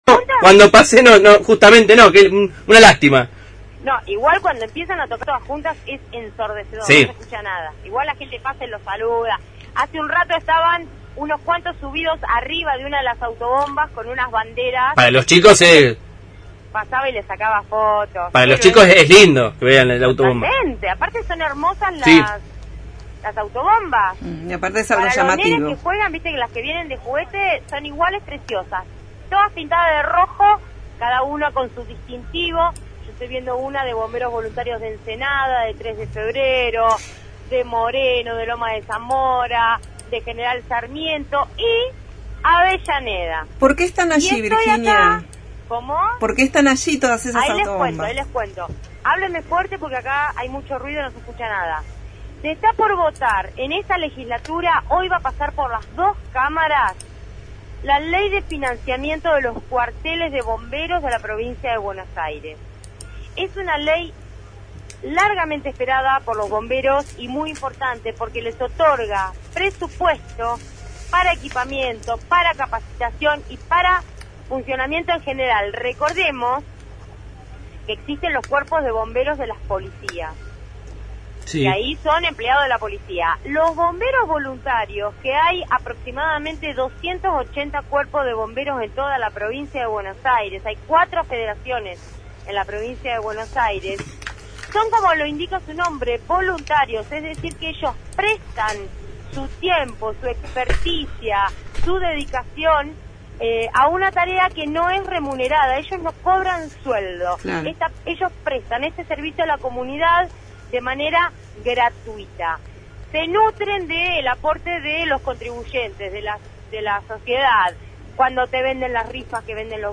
MÓVIL/ Ley de financiamiento a los cuarteles de bomberos – Radio Universidad
TEMA: Ley de financiamiento a los cuarteles de bomberos de la Provincia de Buenos Aires. Entrevista